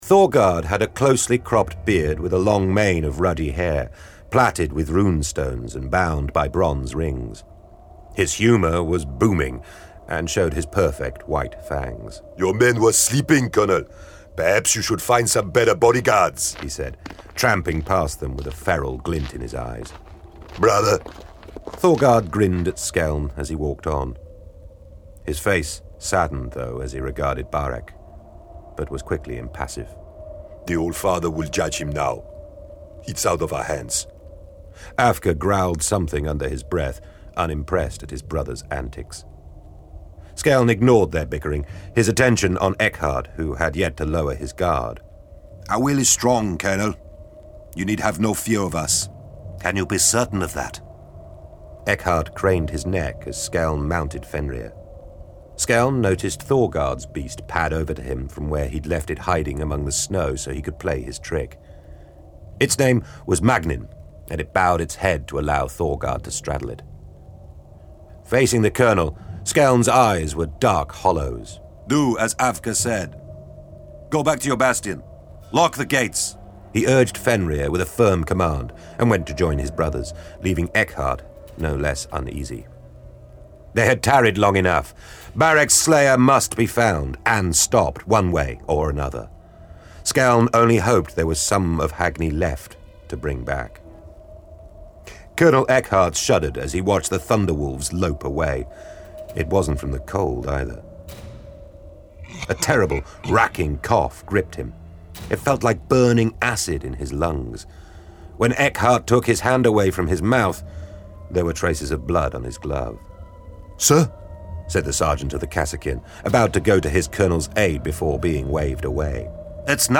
Index of /Games/MothTrove/Black Library/Warhammer 40,000/Audiobooks/Thunder from Fenris